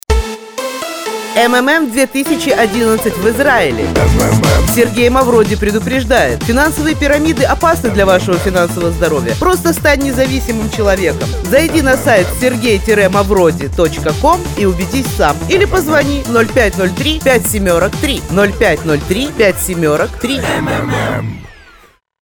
Реклама МММ на радио Категория: Наружная реклама